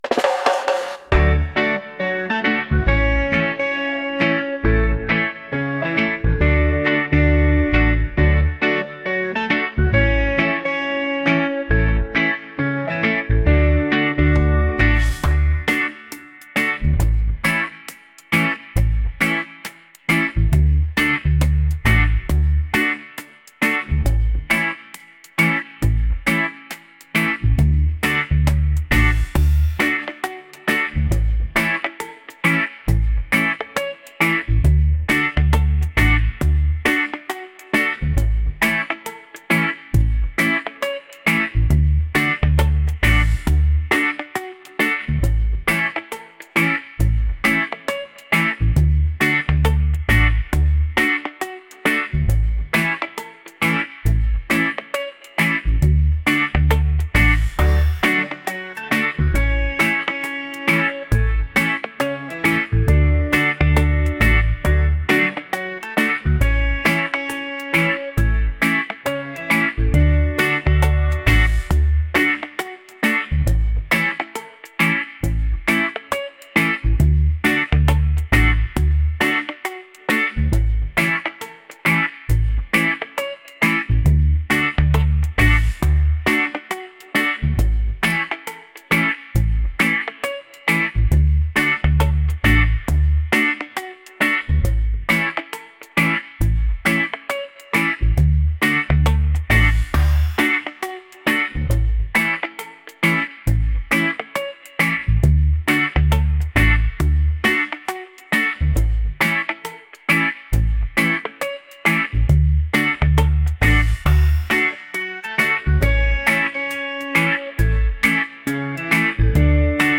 reggae | laid-back | catchy